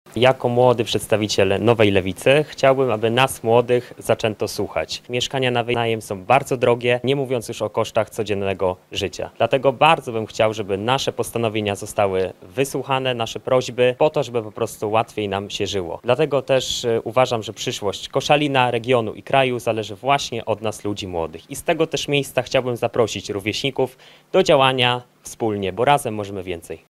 Podczas konferencji prasowej przedstawiciele ugrupowania pochwalili się wdrożonymi postulatami, które miały na celu poprawę sytuacji społecznej i ekonomicznej Polaków.